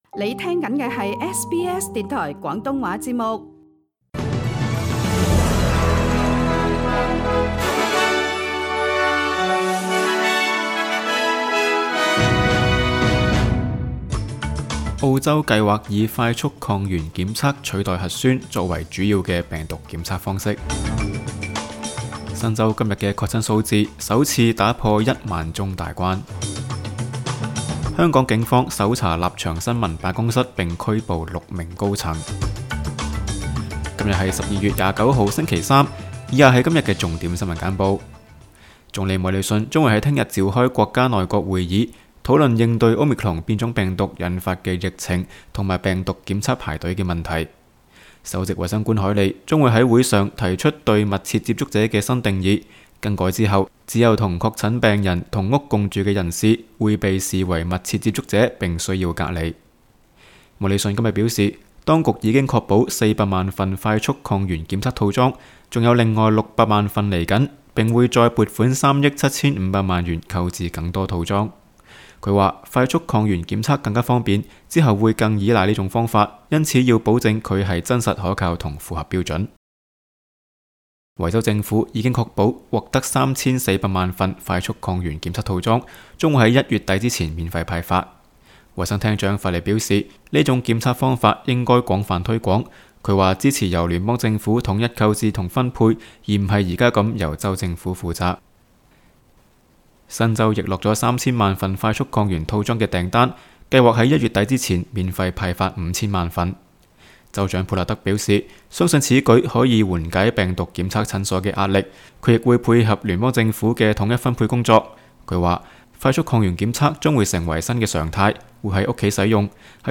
SBS 新聞簡報（12月29日）
SBS 廣東話節目新聞簡報 Source: SBS Cantonese